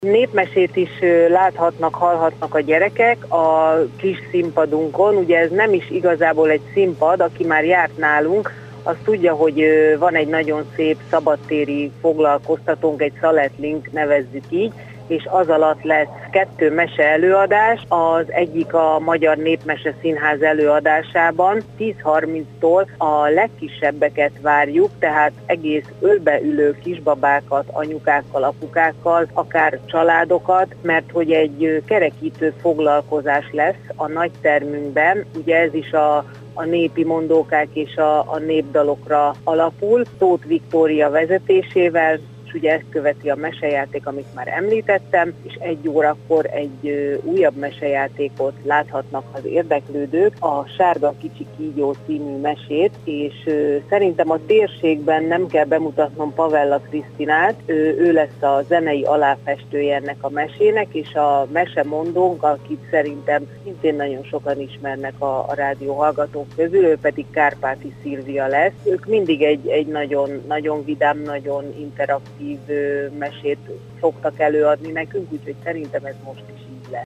Hírek